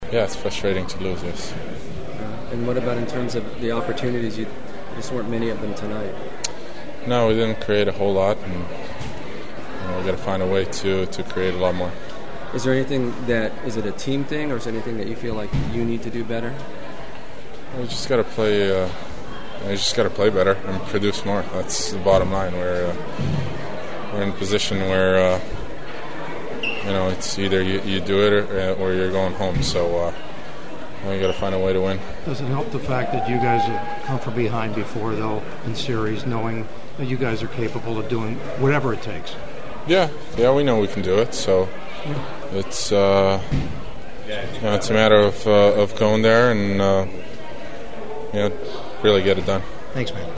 The following are my sounds of the postgame and this one was far from fun to get the losing side to have to comment on this game and what lies ahead…which immediately is a potential season-ending game 5 in Chicago on Saturday.
Kings center Anze Kopitar: